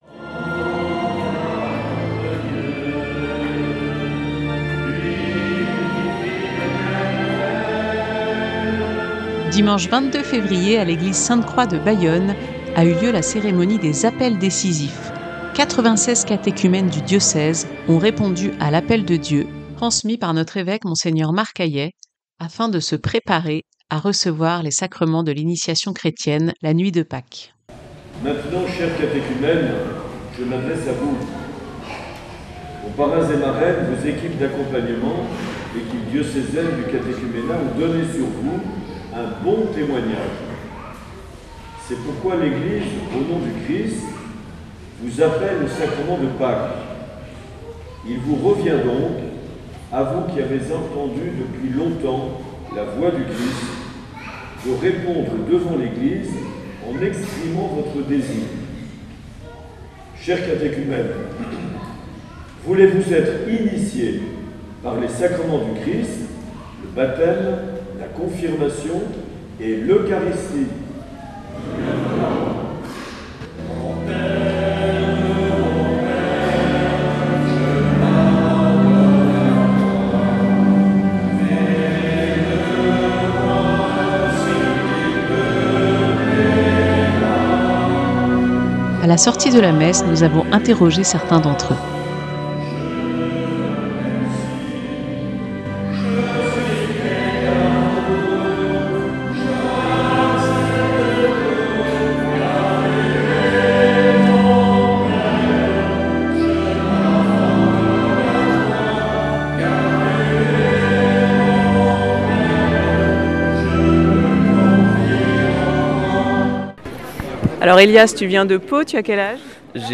Témoignage de catéchumènes venus du Béarn.